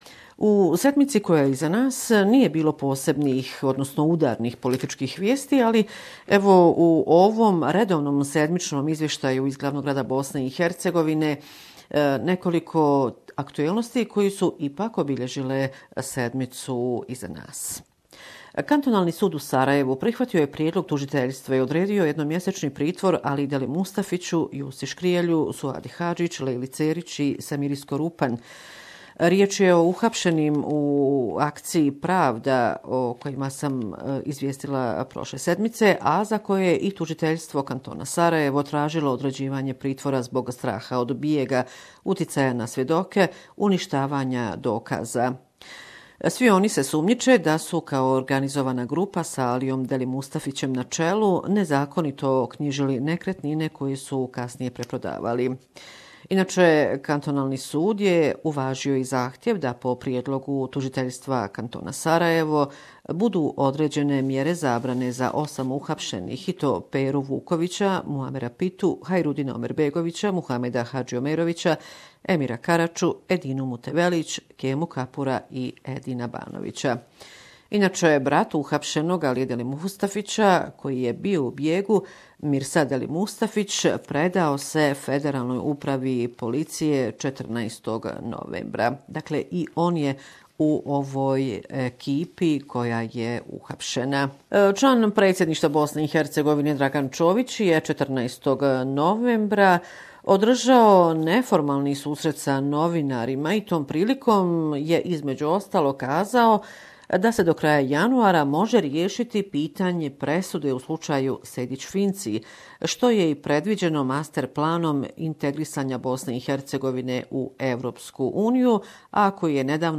Last week report from Bosnia and Herzegovina